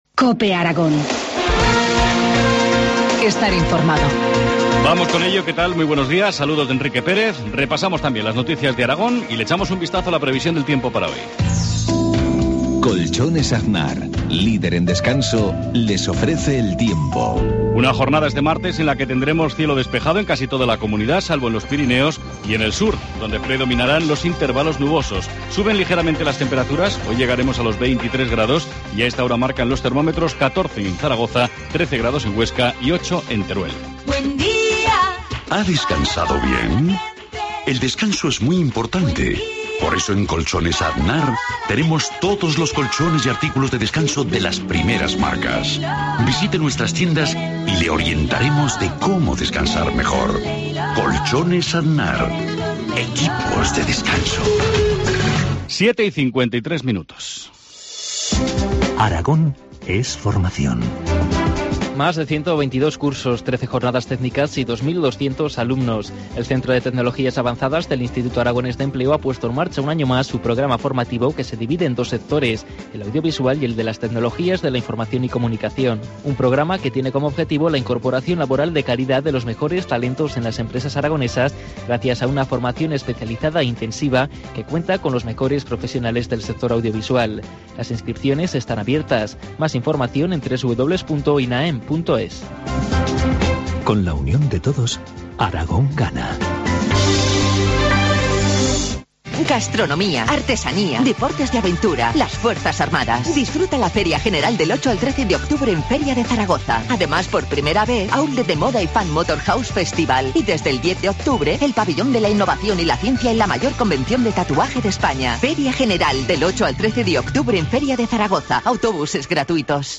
Informativo matinal, martes 8 de octubre, 7.53 horas